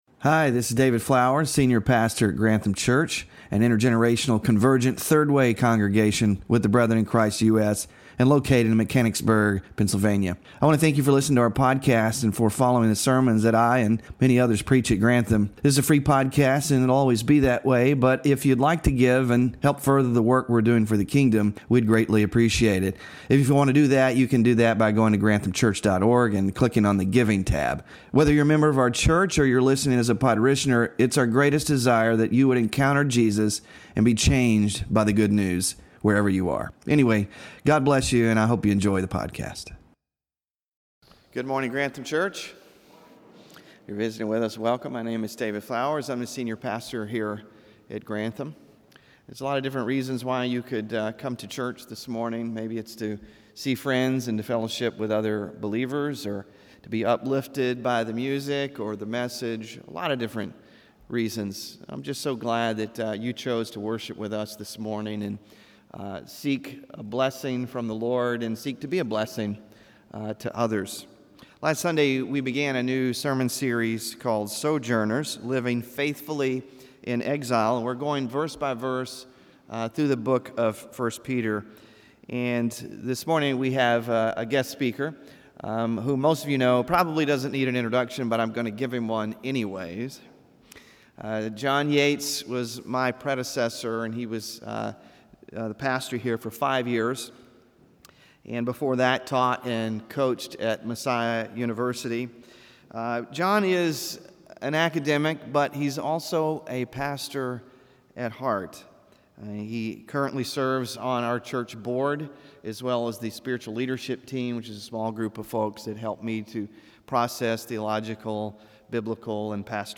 WORSHIP RESOURCES The Way of the Holy Sojourner - Sermon Slides (2 of 9) Small Group Discussion Questions (none) Bulletin (7-13-25)